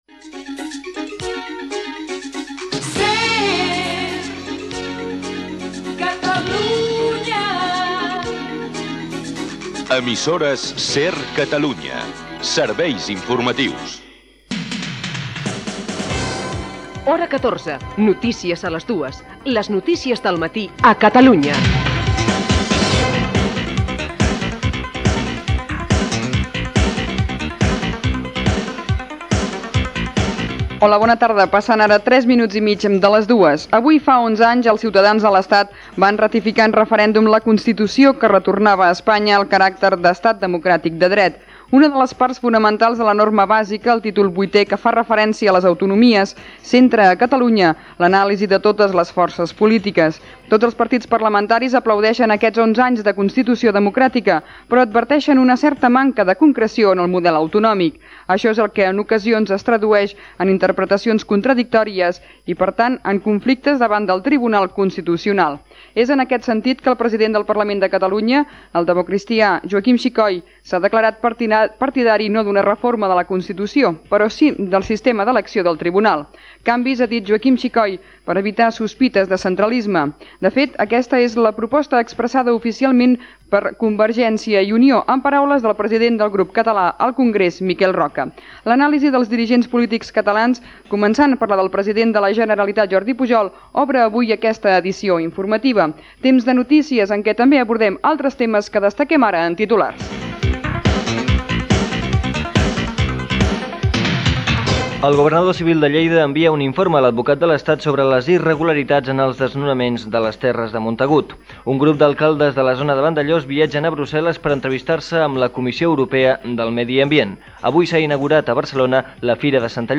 Indicatiu de l'emissora, careta del programa, 11 anys de la Constitució espanyola, titulars, equip, valoracionsdels dels polítics catalans sobre la Constitució espanyola
Informatiu